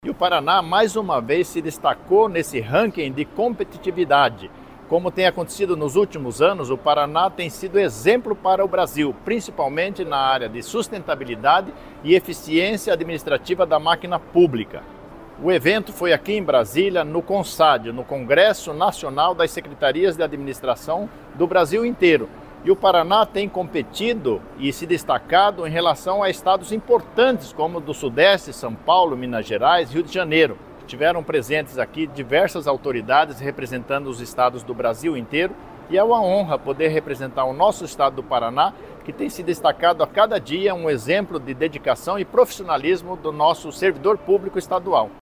Sonora do secretário de Administração e da Previdência, Luizão Goulart, sobre o Ranking de Competitividade dos Estados